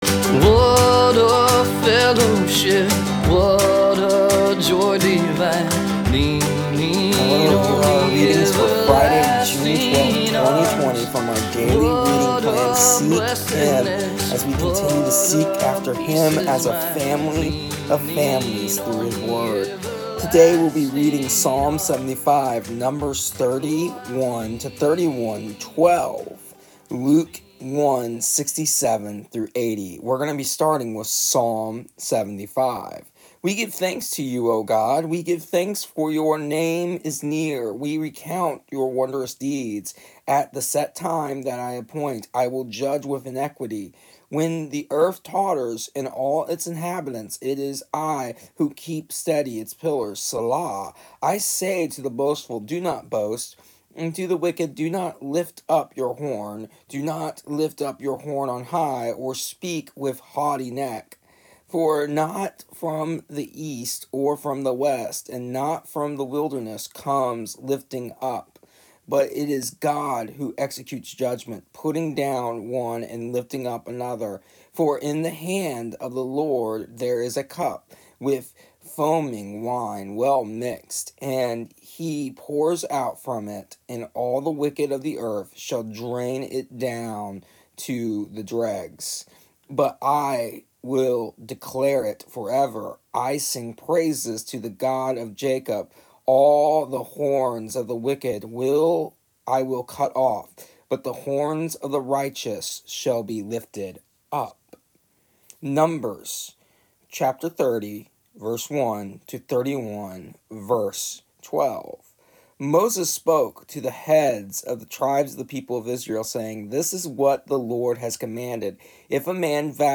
Here is an audio version of our daily readings from our daily reading plan Seek Him for June 12th, 2020.